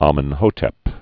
mən-hōtĕp, ămən-) also Am·e·no·phis III (ămə-nōfĭs) Died c. 1372 BC.